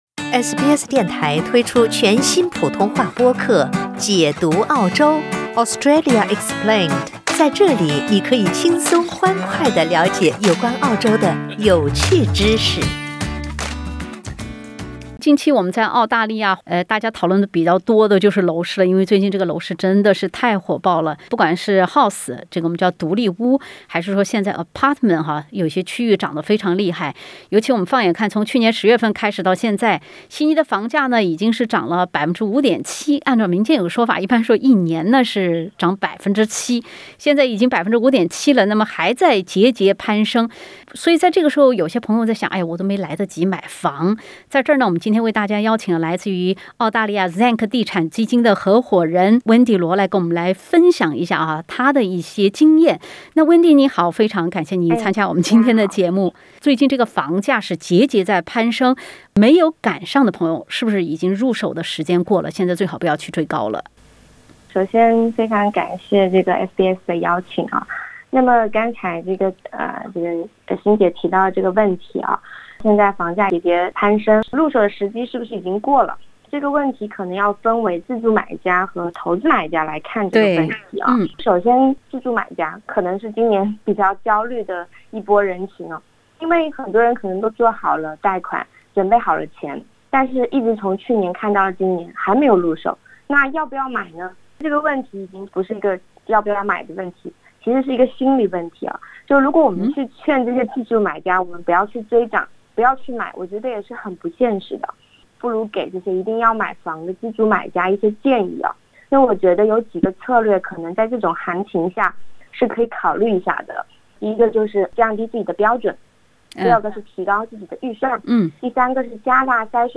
房价飞涨，目前看似安全的低利率一旦打破，预备多少现金流才不会出现“断供”？（点击封面图片，收听完整采访）